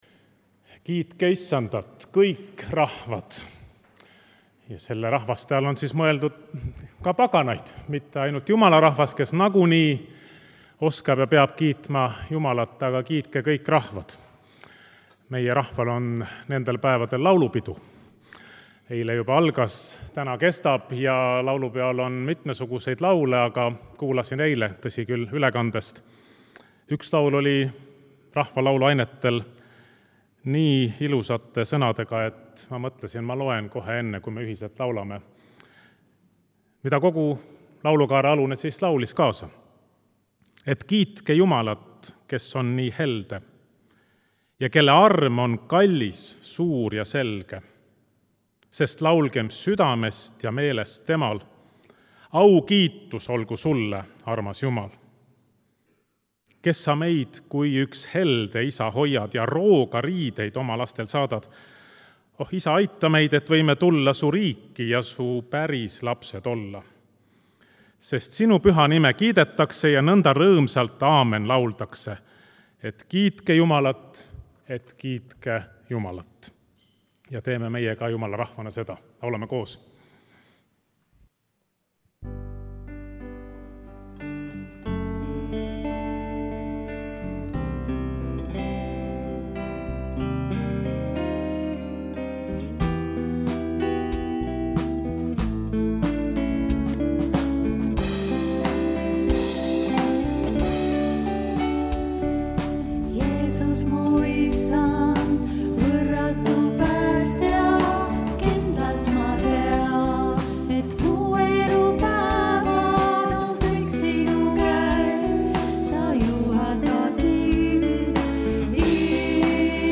Kõik jutlused